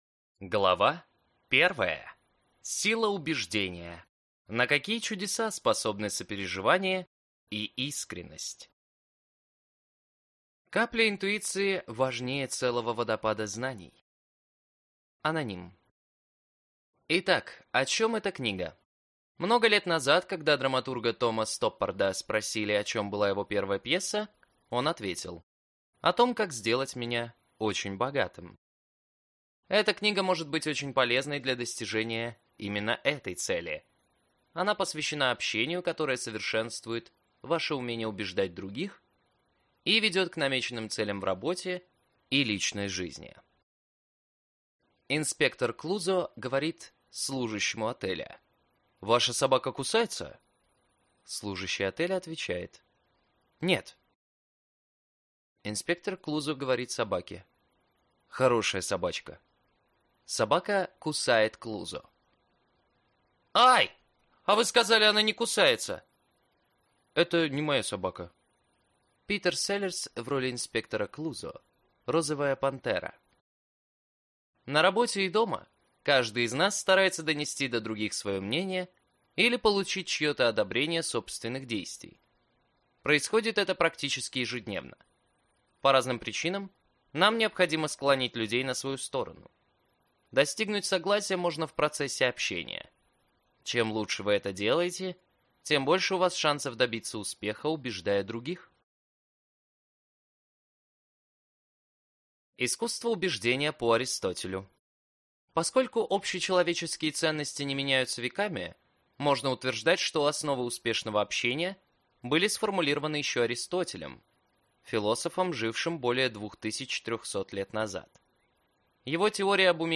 Аудиокнига Сила убеждения. Искусство оказывать влияние на людей | Библиотека аудиокниг